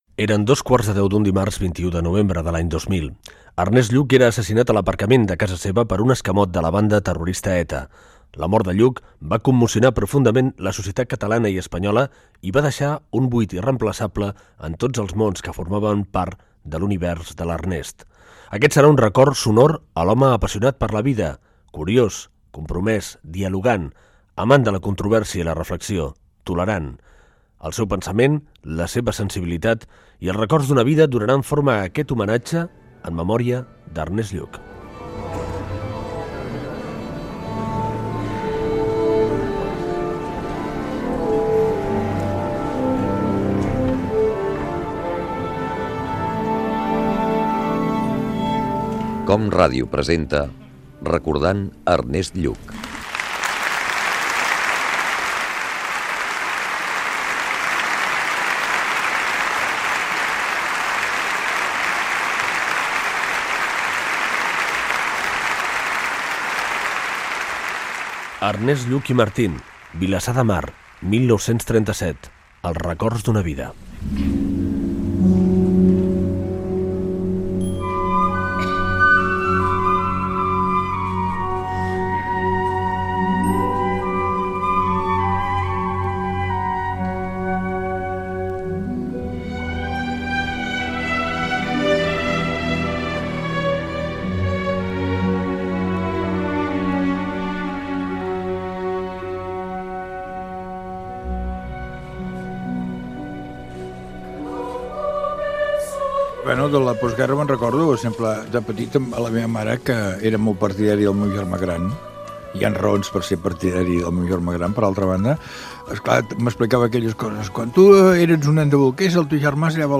Programa especial emès amb motiu del primer aniversari de l'assassinat del polític Ernest Lluch per la banda terrorista ETA.
dades biogràfiques amb explicacions de Lluch de la seva infantesa i joventut. Records de persones que l'havien conegut.